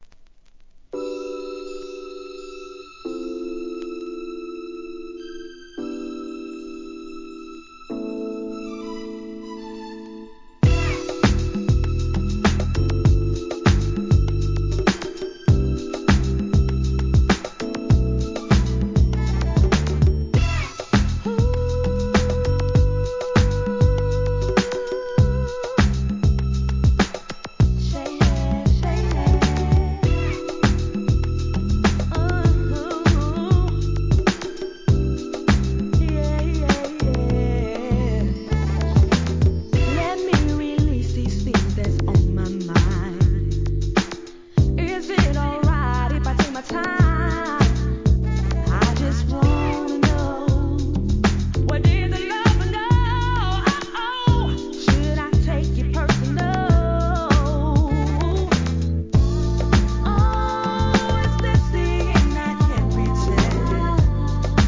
HIP HOP/R&B
リリースは少ないもののキャリアのあるR&Bシンガー!